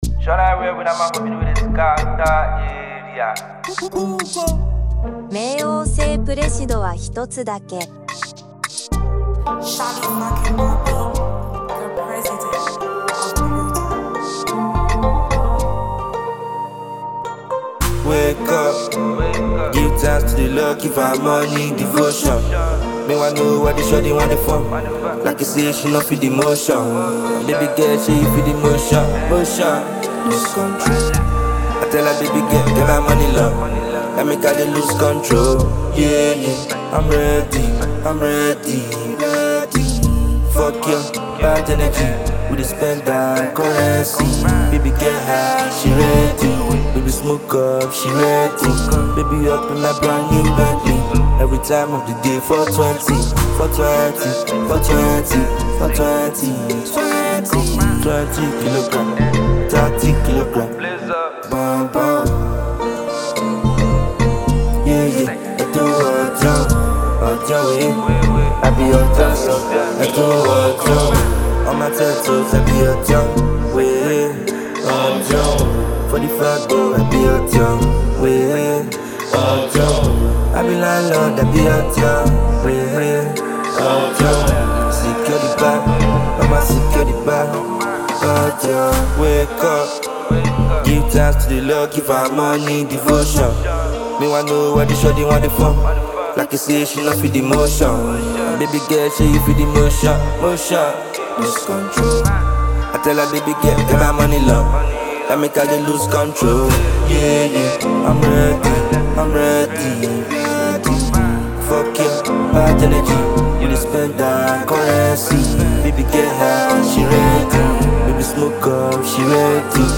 delivery is confident and conversational